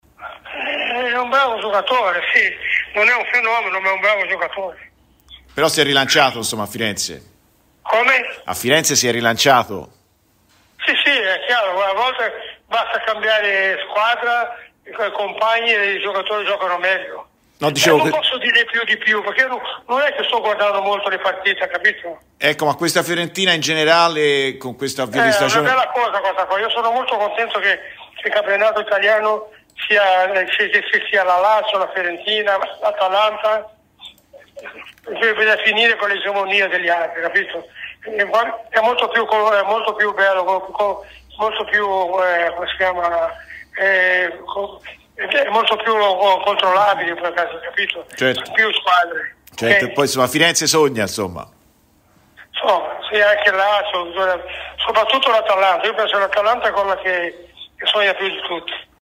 José Altafini è intervenuto a Radio FirenzeViola durante "Viola Amore Mio" commentando così il rendimento di Moise Kean: "A volte basta cambiare squadra e compagni... E i calciatori giocano meglio".